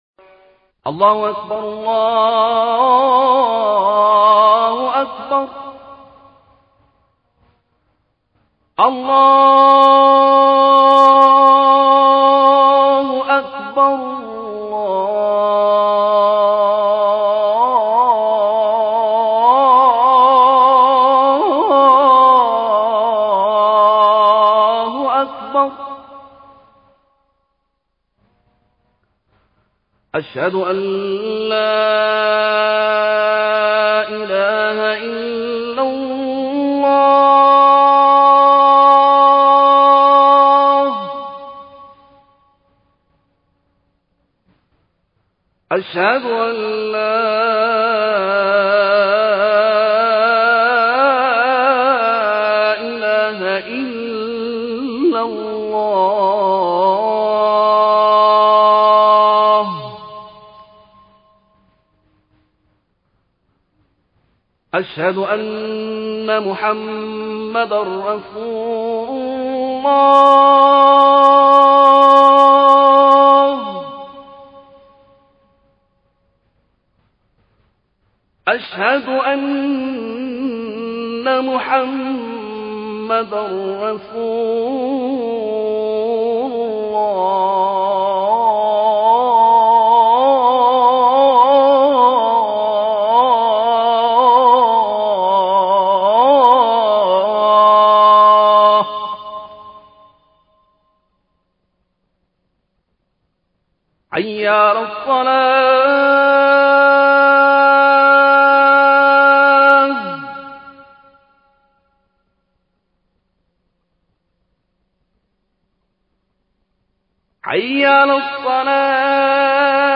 NoCall to prayer Mp3 Download Size in Bytes
2.Azan
azan.mp3